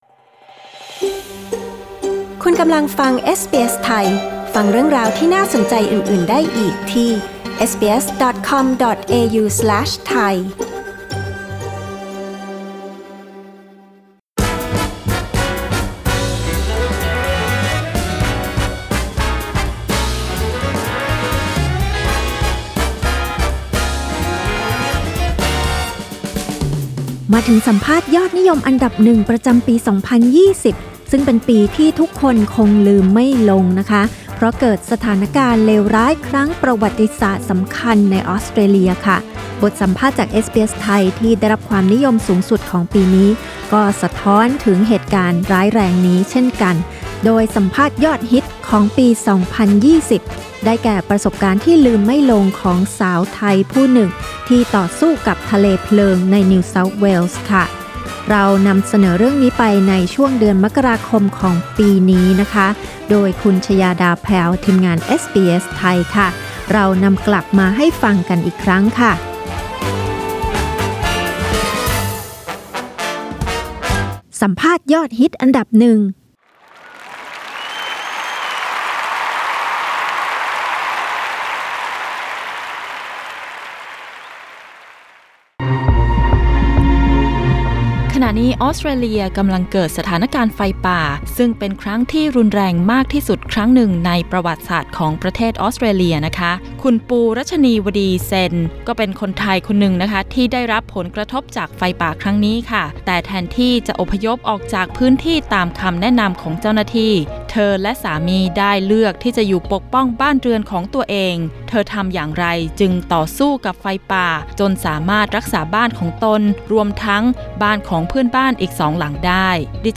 สัมภาษณ์ยอดนิยมอันดับ 1 ประจำปี 2020